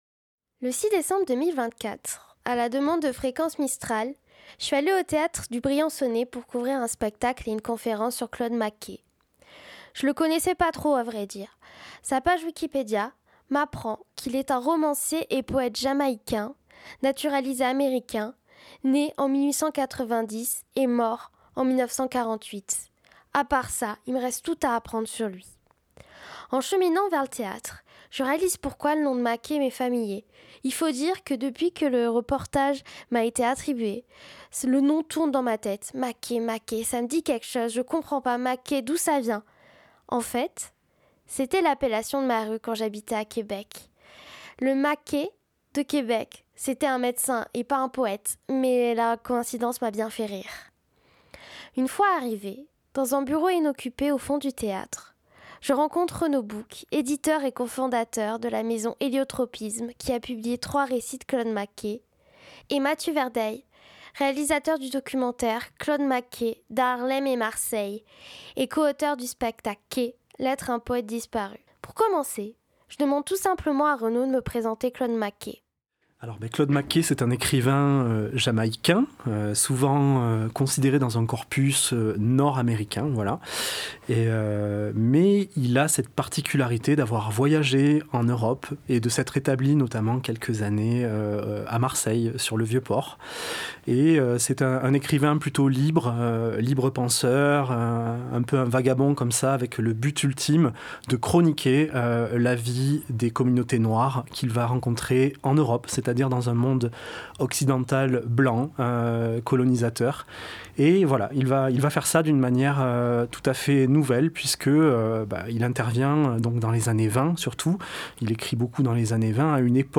Reportage McKay